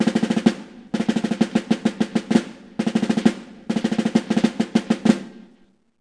snare.mp3